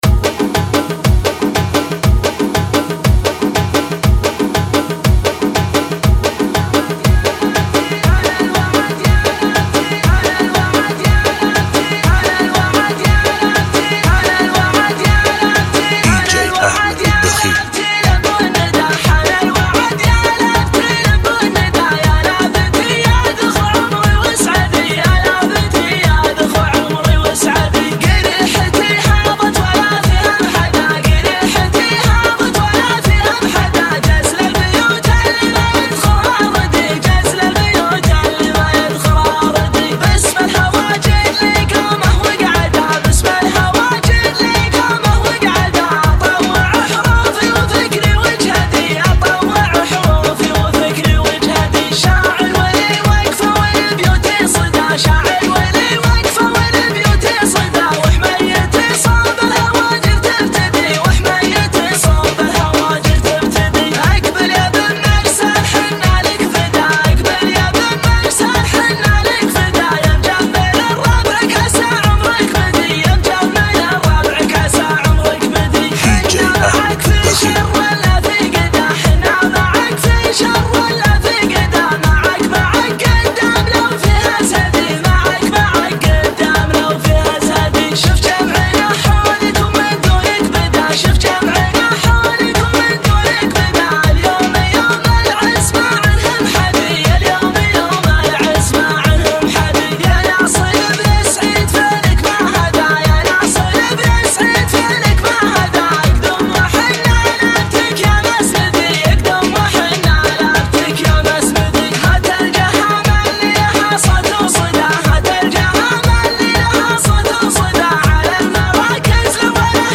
شيله
Funky Remix